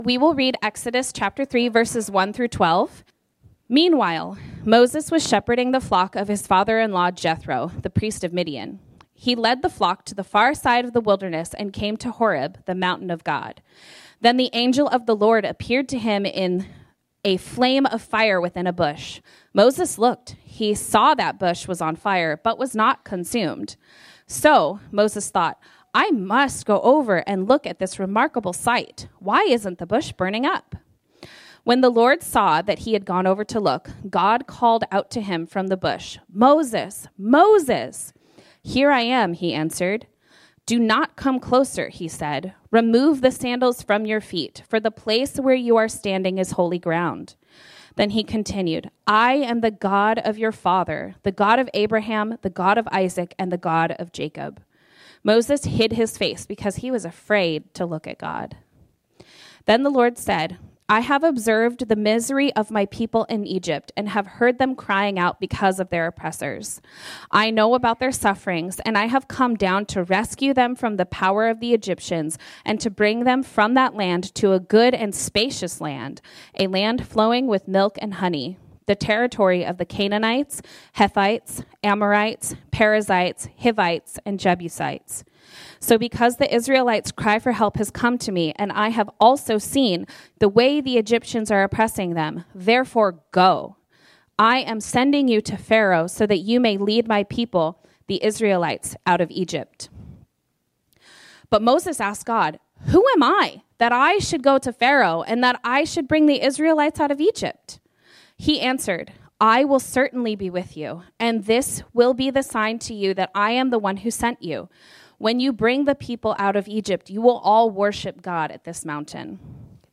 This sermon was originally preached on Sunday, January 19, 2025.